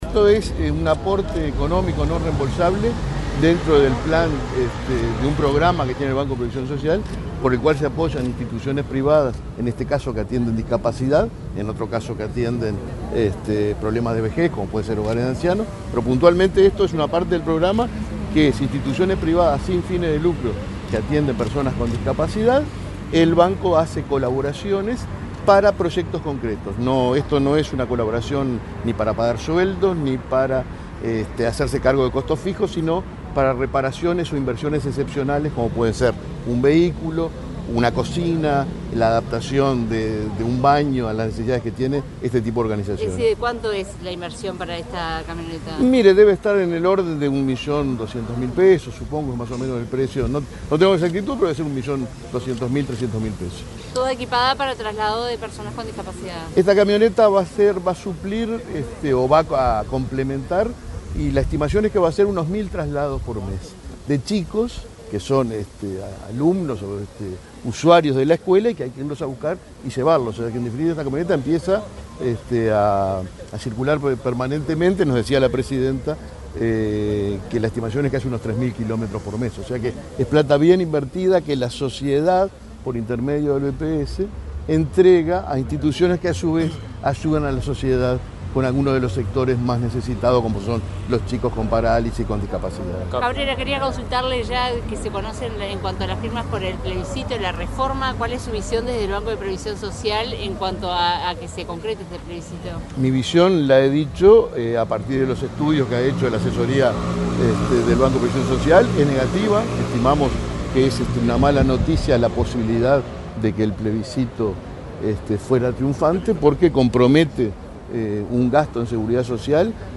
Declaraciones del presidente del BPS, Alfredo Cabrera
El presidente del Banco de Previsión Social, Alfredo Cabrera, dialogó con la prensa, luego de participar en el acto de entrega a la Escuela Roosevelt,